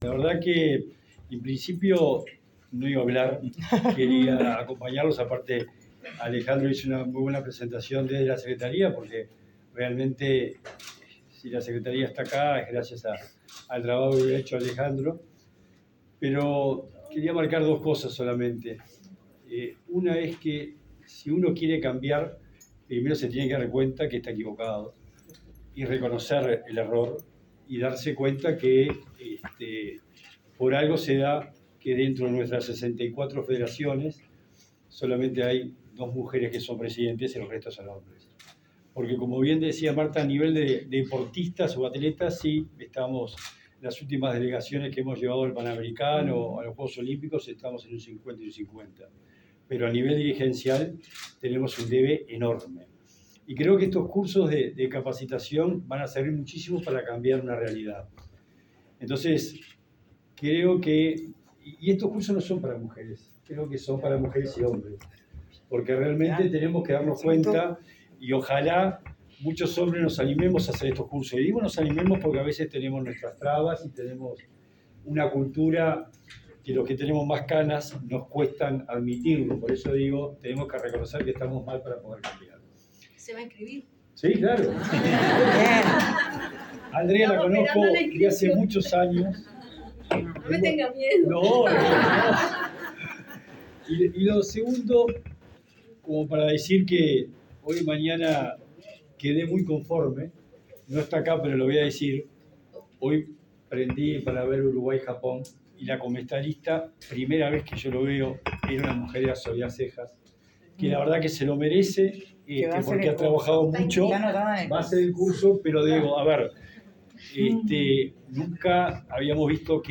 Palabras del secretario nacional del Deporte, Sebastián Bauzá
El secretario nacional del Deporte, Sebastián Bauzá, participó del lanzamiento del primer curso énero, sobre género, deporte y políticas públicas.